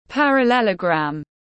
Hình bình hành tiếng anh gọi là parallelogram, phiên âm tiếng anh đọc là /ˌpær.əˈlel.ə.ɡræm/.
Parallelogram /ˌpær.əˈlel.ə.ɡræm/